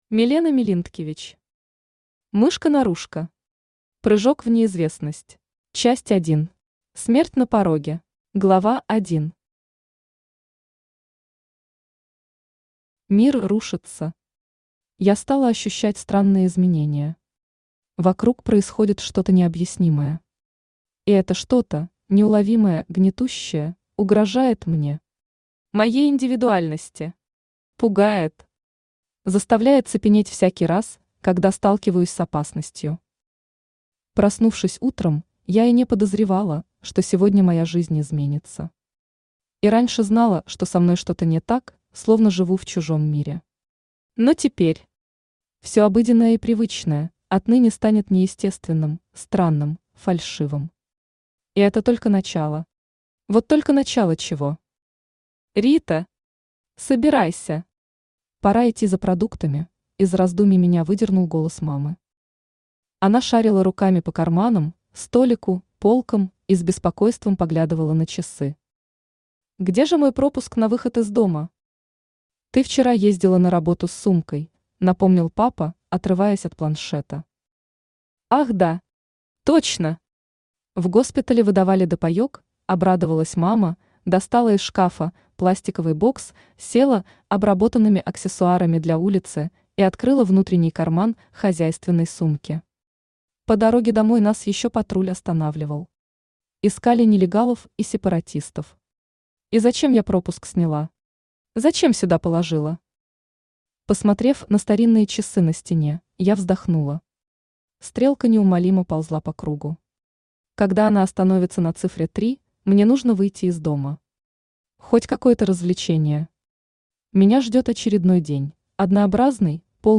Аудиокнига Мышка-норушка. Прыжок в неизвестность.
Автор Милена Миллинткевич Читает аудиокнигу Авточтец ЛитРес.